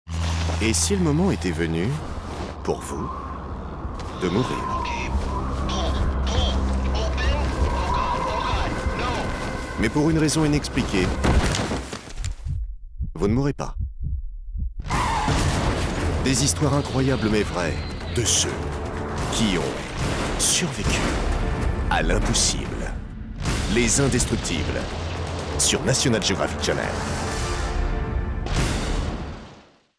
Voix-off pour "Les Indestructibles" : élégance, questionnement et percussion
Voix interpellante et élégante. Avec renversement à la fin.
Pour « Les Indestructibles », le défi était d’adopter une tonalité à la fois élégante, questionnante et percutante.
Avec une hauteur de voix grave, j’ai cherché à créer une atmosphère intense et captivante.